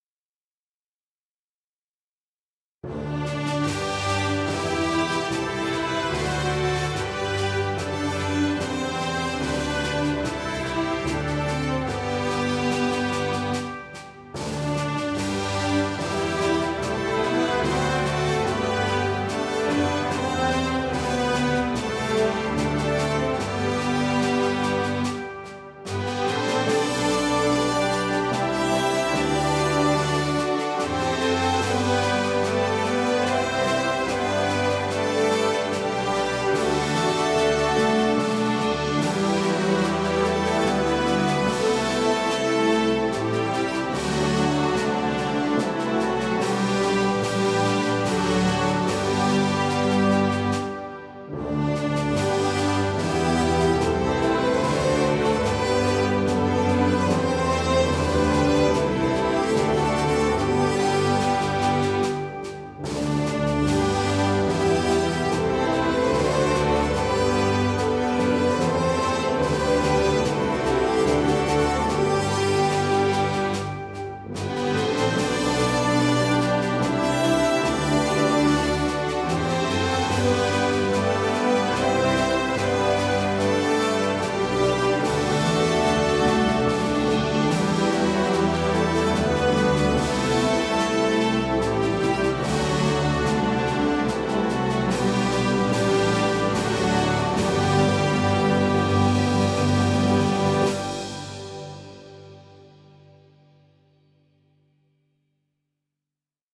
Nationaal_volkslied_Wilhelmus_van_NassouweAangepaste_begeleidingstrack-1.mp3